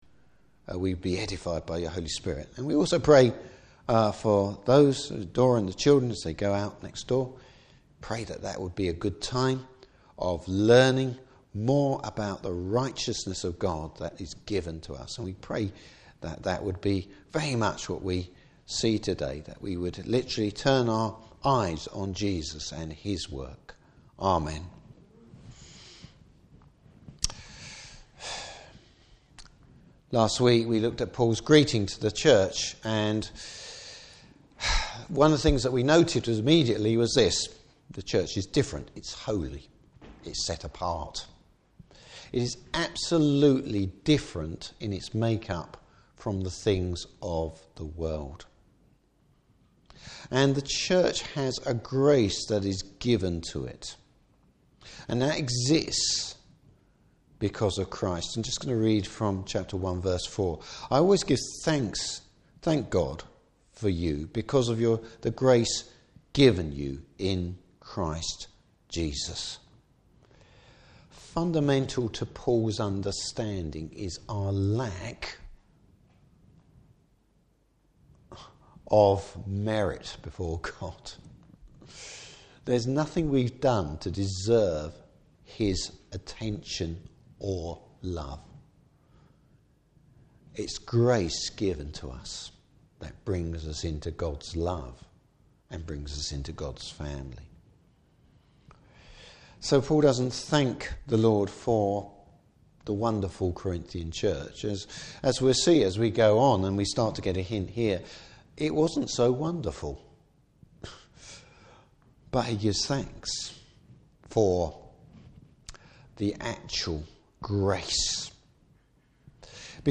Service Type: Morning Service True Christian unity is found in the Cross.